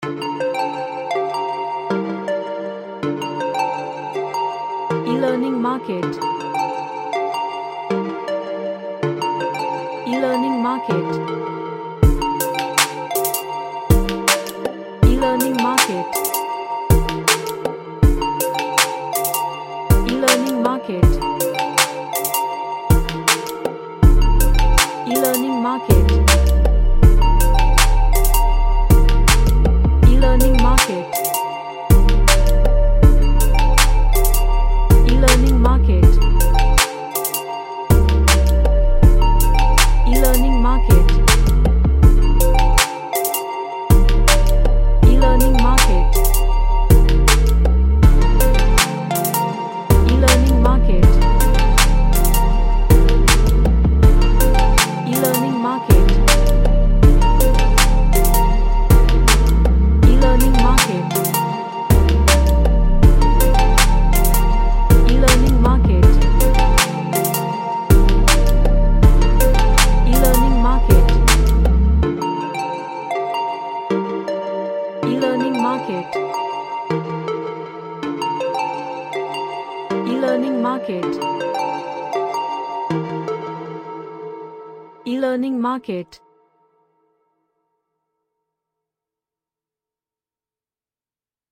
A wobbly pop track with pop drums
Chill Out